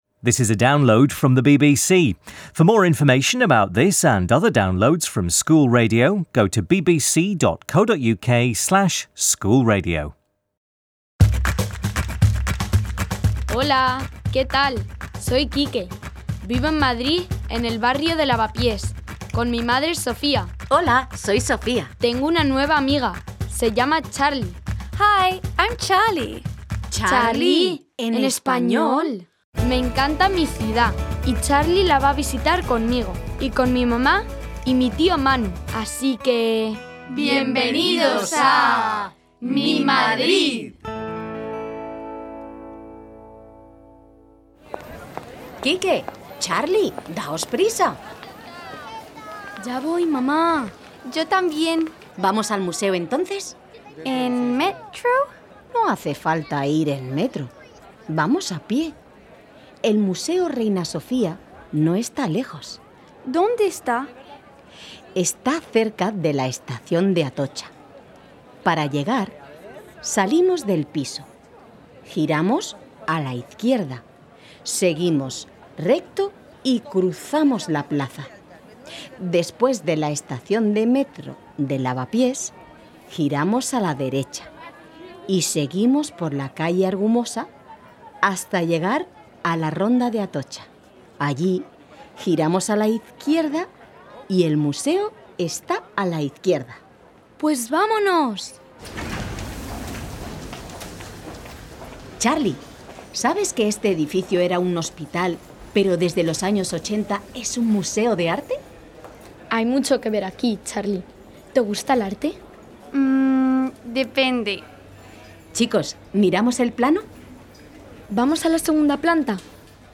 Sofía takes Charlie and Quique to the Reina Sofía Museum where they meet Blanca, a museum guide, who tells them about some of Spain's most famous artists. Sofía tells a story about a bear which is lost in the museum and Uncle Manu sings about the joy of painting. Key vocabulary includes giving directions and key grammar includes adjectival agreement and word order.